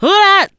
OUHAHIPHOP.wav